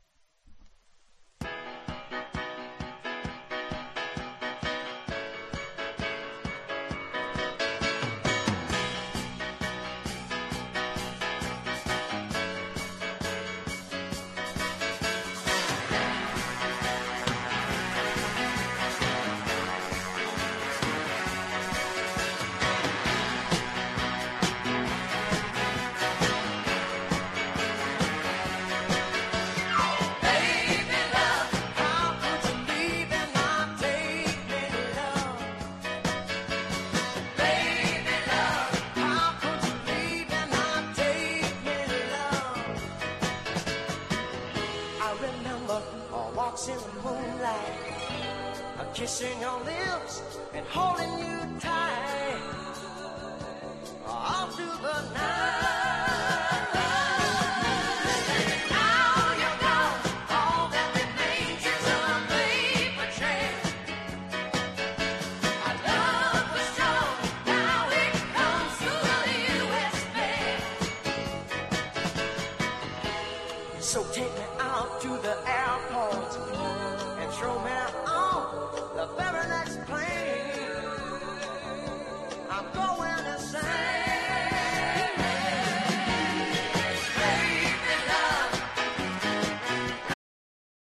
プレAORな匂いもする